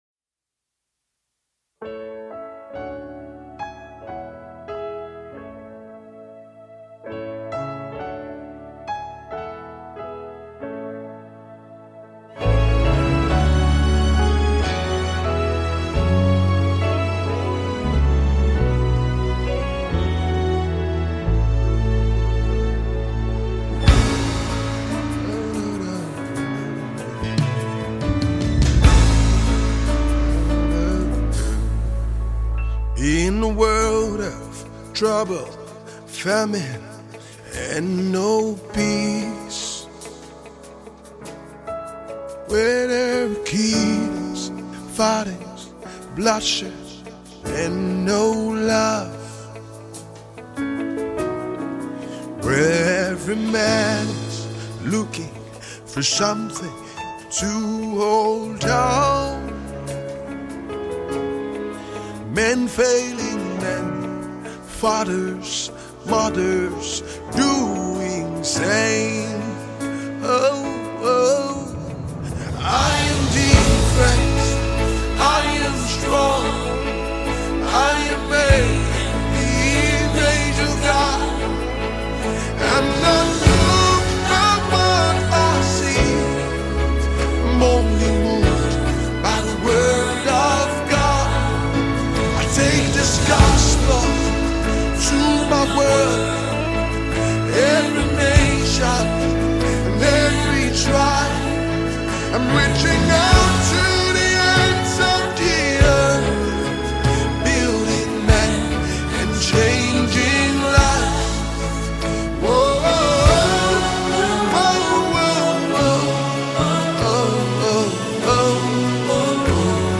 talented singer-songwriter and recording artiste